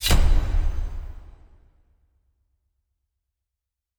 Fantasy Interface Sounds
Special Click 14.wav